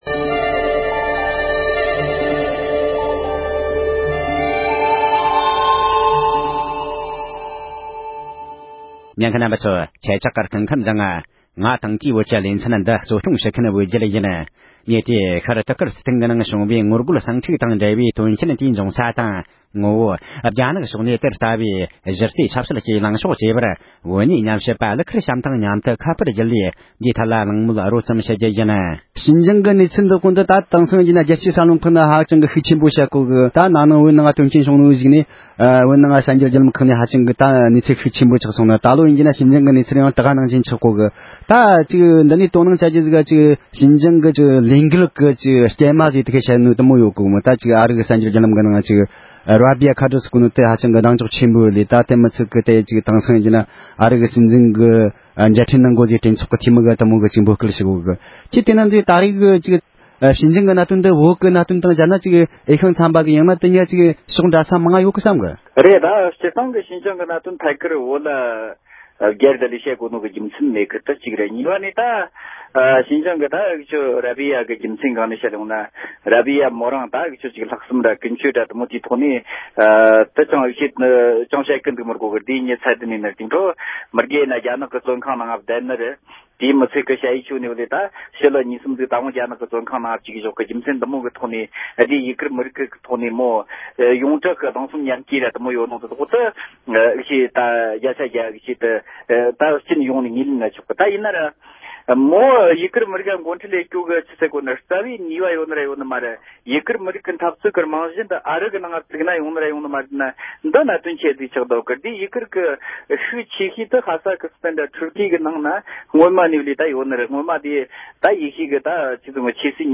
ཤར་ཏུར་ཀི་སི་ཐན་གྱི་ངོ་རྒོལ་ཟིང་འཁྲུག་གི་ངོ་བོ་དང་རྒྱ་གཞུང་གིས་དེར་འཛིན་པའི་ཆབ་སྲིད་ལངས་ཕྱོགས་ཐོག་གླེང་མོལ།